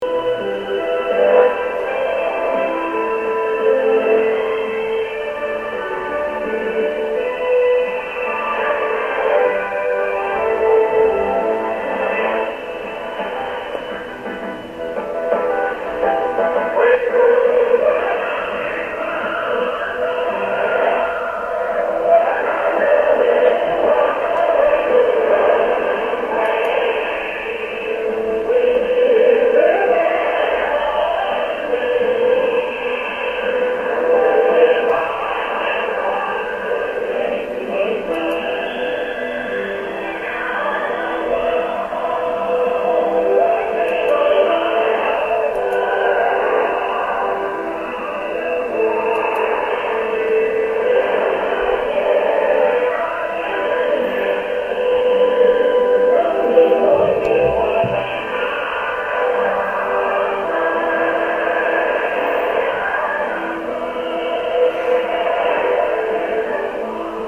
По тибетскому радио PBS Xizang постоянно передают песни групп типа "The Snow Lotus Trio", но я ищу название дуэта исполнителей The Heavenly Tibet очень мелодичная песня (мужской-тенор, женский-сопрано).
Radio PBS Xizang, Lhasa.mp3